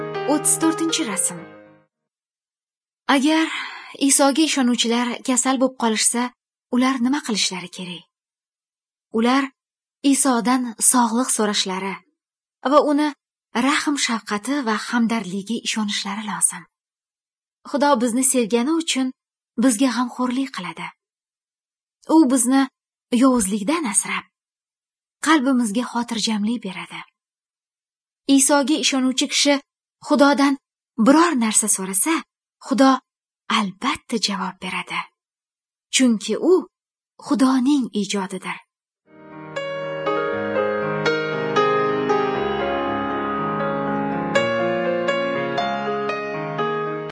Good News (Female)